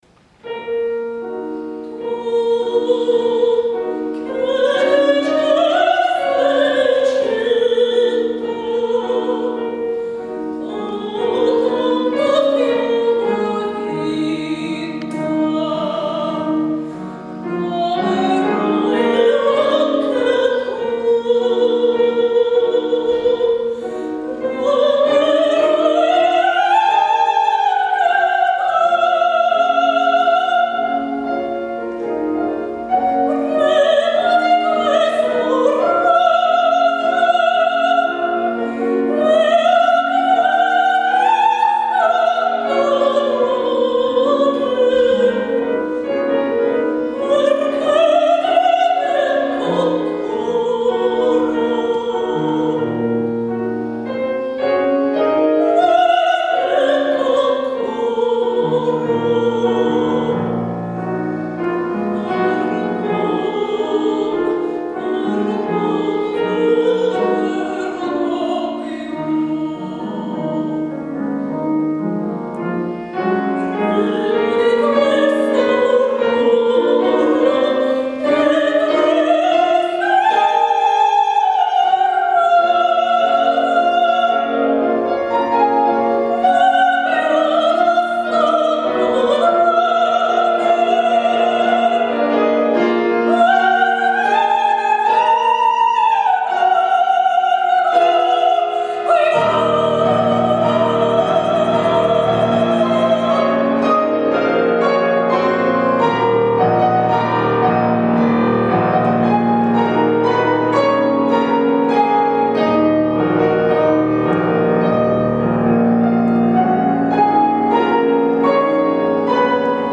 драматическое сопрано